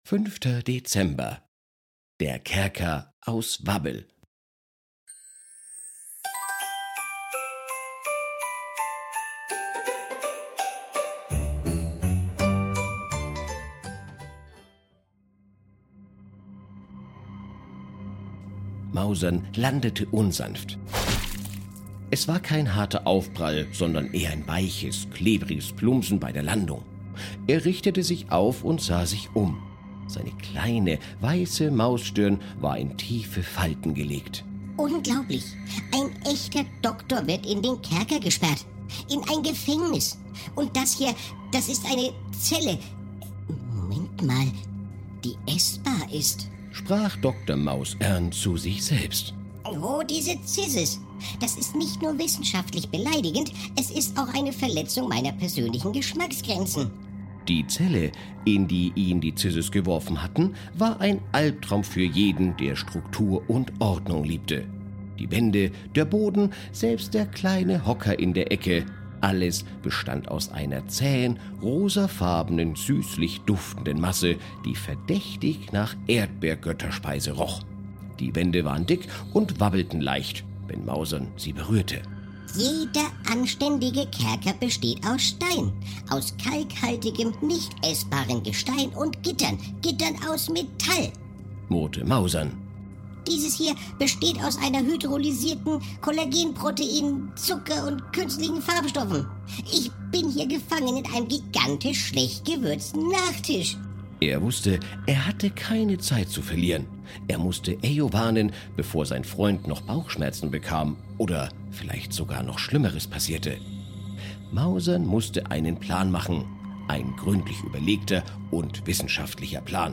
Ein Kinder Hörspiel Adventskalender